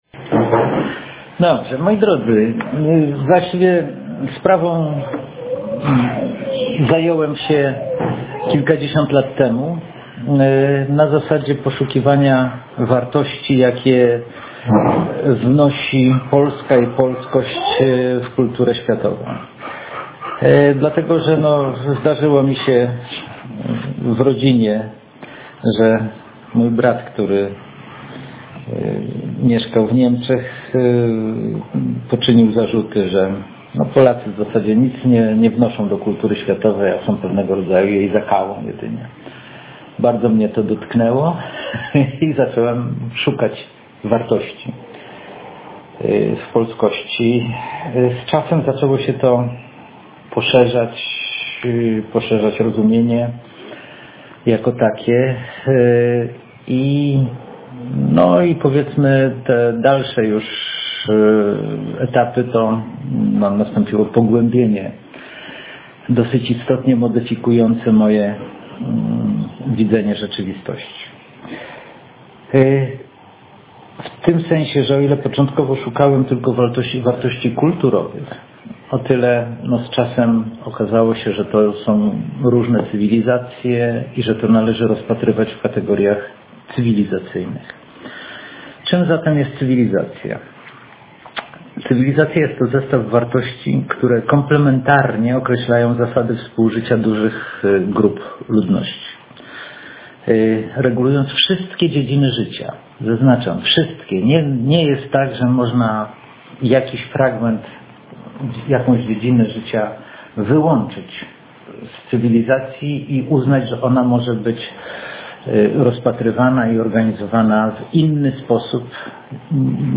Nagranie audio prelekcji opisującej ideę Cywilizacji Polskiej